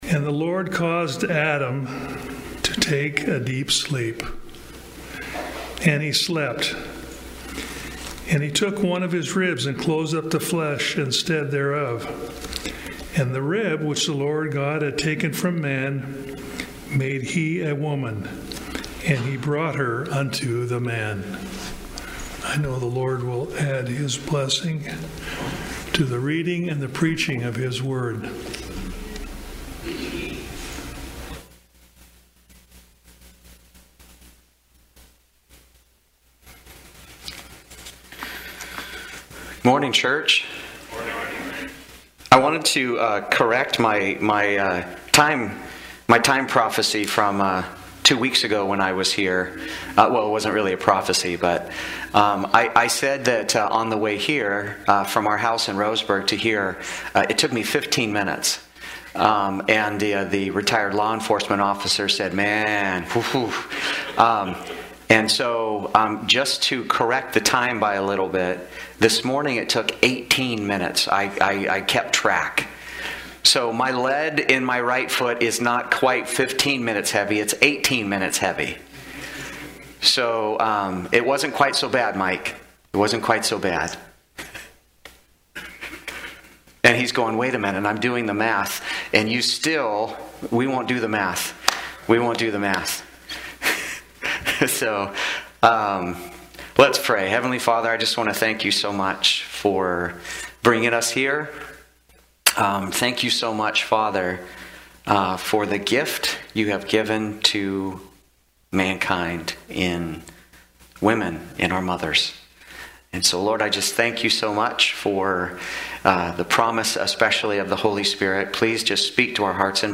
Sermons and Talks